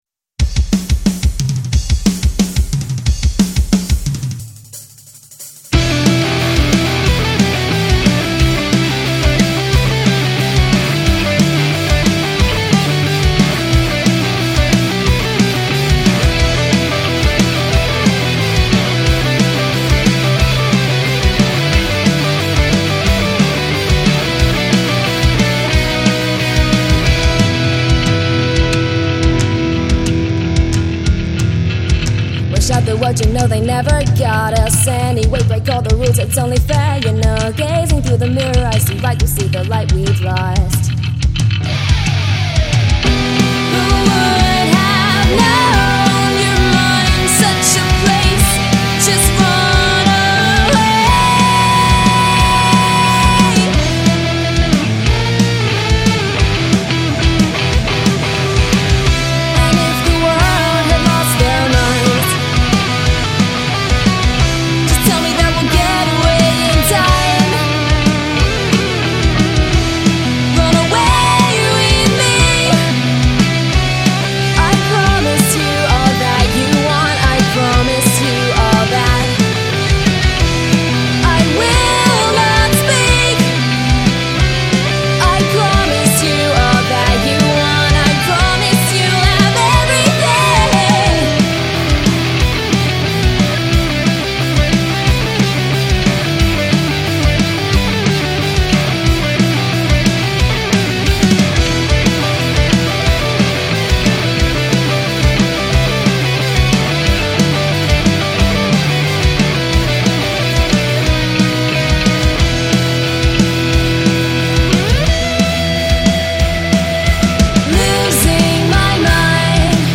vocals
bass
rhythm guitar
lead guitar
drums